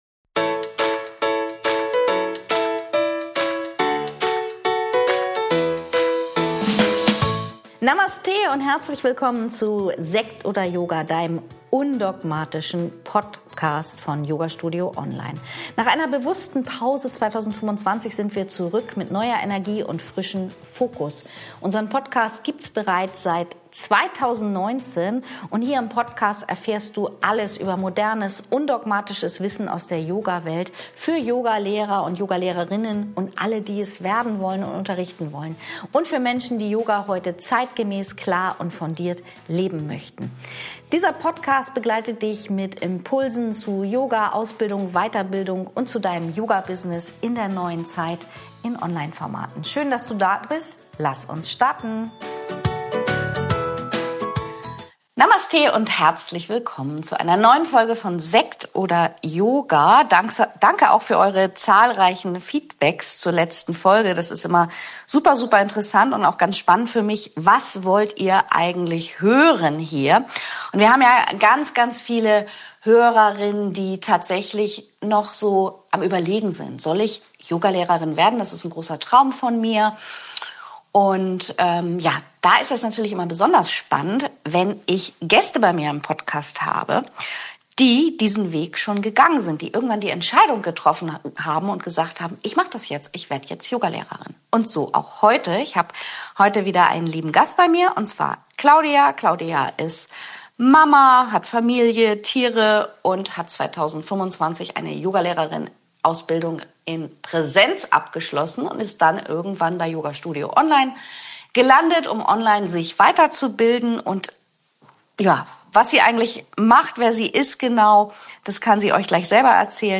Heute im Gespräch